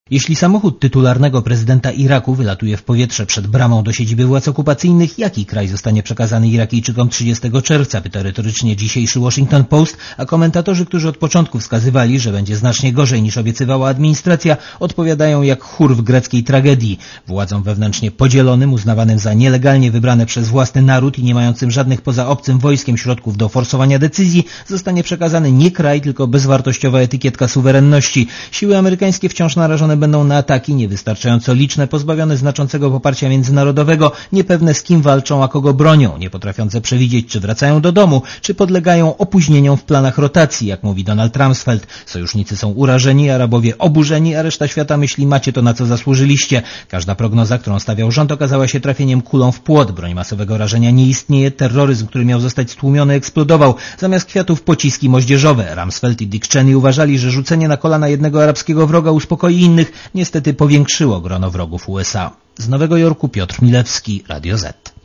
Posłuchaj relacji nowojorskiego korespondenta Radia ZET (252 KB)